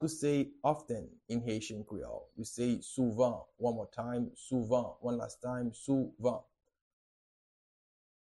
Pronunciation and Transcript:
How-to-say-Often-in-Haitian-Creole-Souvan-pronunciation-.mp3